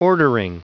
Prononciation du mot ordering en anglais (fichier audio)
Prononciation du mot : ordering